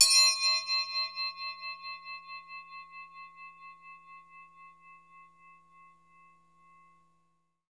Index of /90_sSampleCDs/Roland LCDP03 Orchestral Perc/PRC_Orch Toys/PRC_Orch Triangl
PRC TREM.T0C.wav